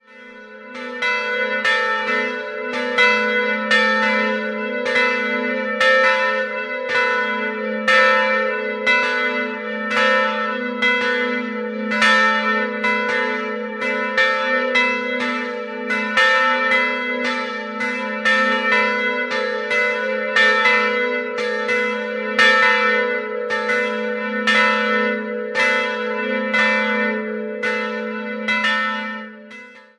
September 1960 konnte sie schließlich eingeweiht werden. 2-stimmiges Geläute: a'-h' Die Glocken wurden 1960 von der Firma Bachert gegossen.